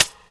Track 14 - Hi-Hat OS 02.wav